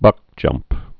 (bŭkjŭmp)